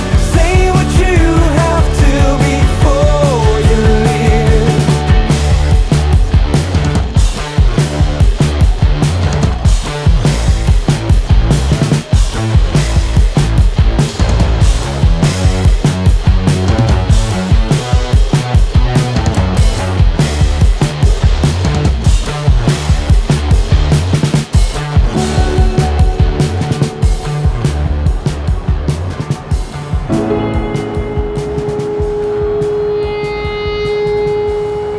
Жанр: Иностранный рок / Рок / Инди